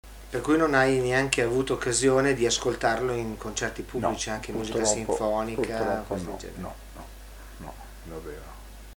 Una intervista